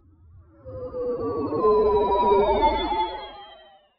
TM88 LandingTransition.wav